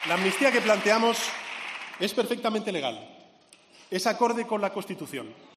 Así lo ha dicho durante su intervención en el pleno de investidura en el Congreso, en la que ha dejado claro que la amnistía se aprobará "bajo luz y taquígrafos, con total transparencia": "No será un ataque a la Constitución del 78, sino todo lo contrario, una muestra más de su fortaleza y vigencia